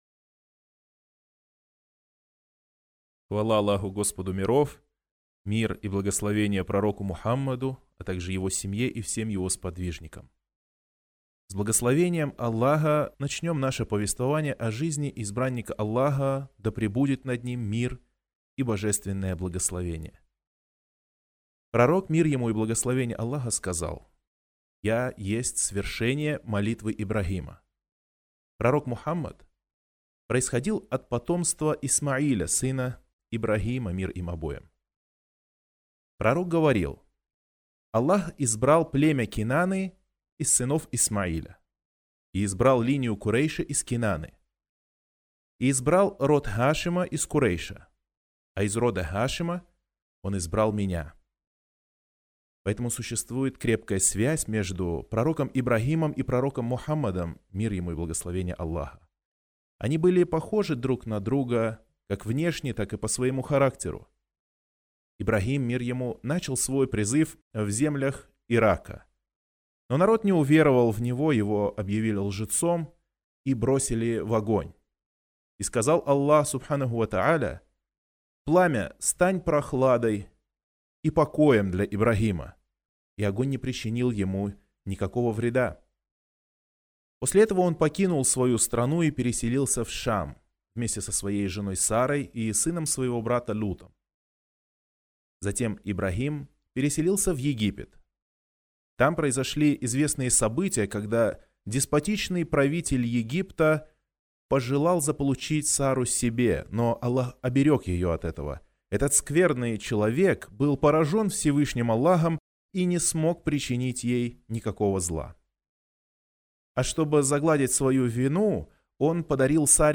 Стенограмма. Лекция №1